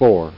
Four! Sound Effect
four.mp3